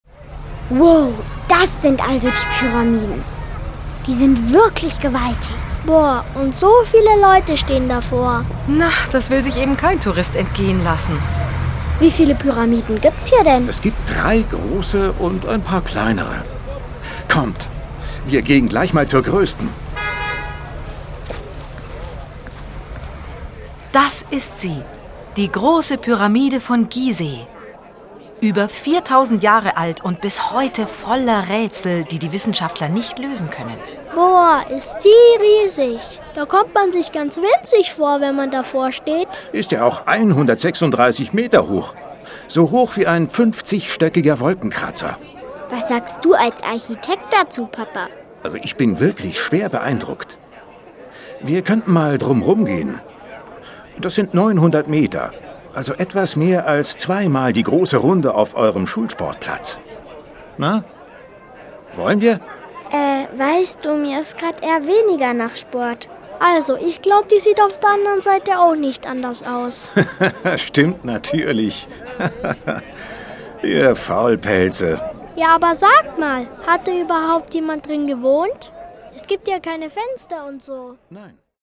Lustige Reime und Songs zum Mitsingen
Hier gibt es jede Menge Wissen, verpackt in unterhaltsame Dialoge und originelle Reime. Tolle Lieder laden außerdem zum Mitsingen ein.